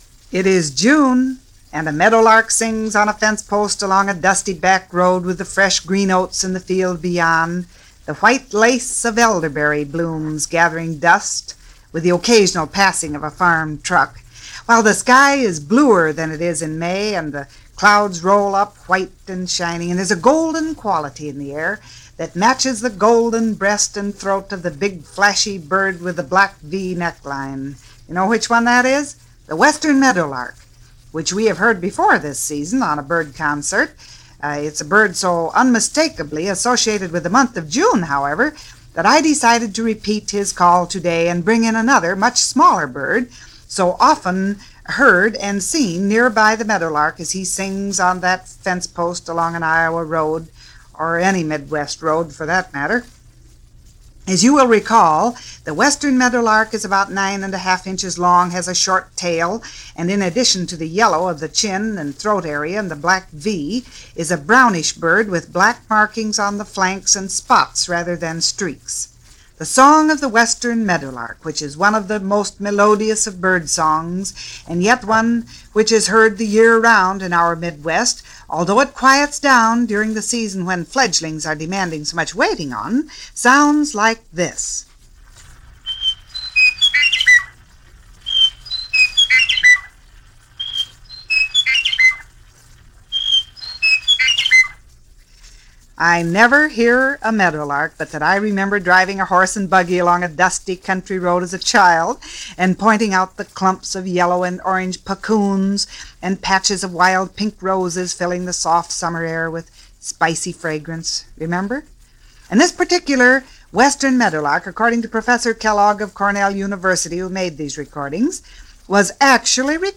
Birds this weekend; specifically, The Meadowlark – primarily, the mid-west – most importantly, from 1955.
A Meadowlark in 1955 – I was serious – it really IS about bird calls.